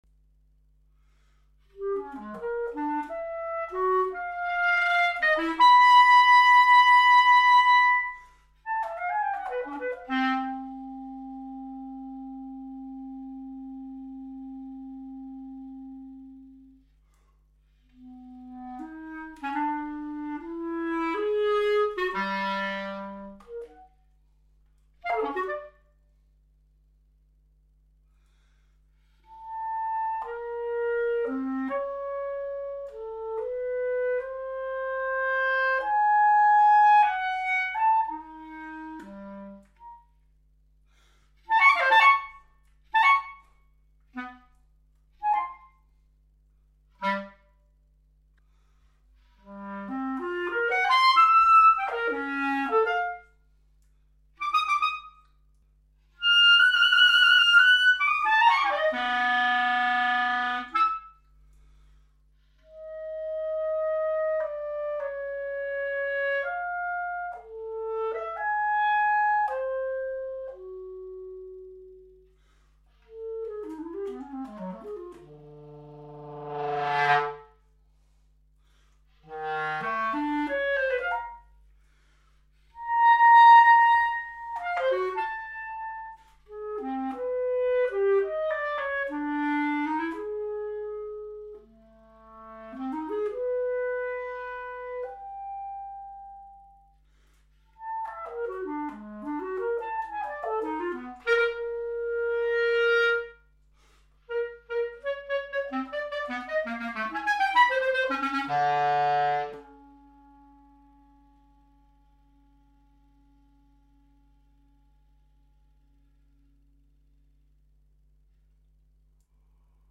Confiée à la clarinette solo, l’œuvre se présente comme un dialogue imaginaire entre le compositeur (puis l’interprète) et la dédicataire du morceau.